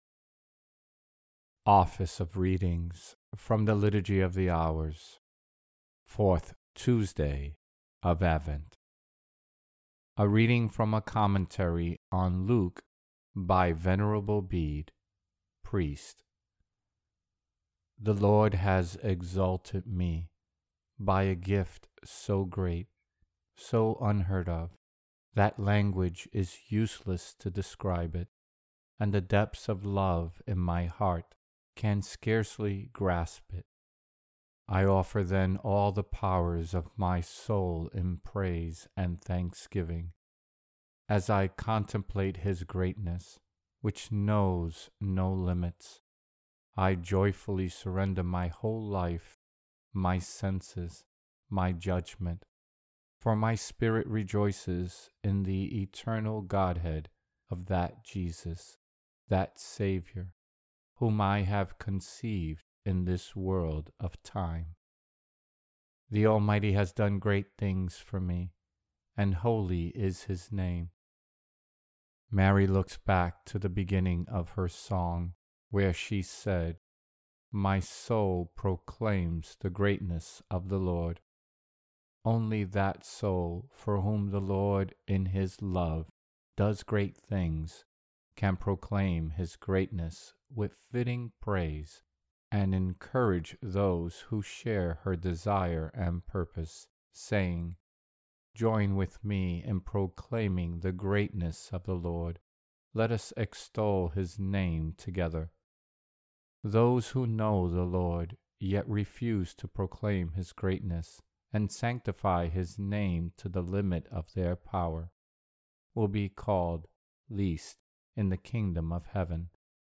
Office of Readings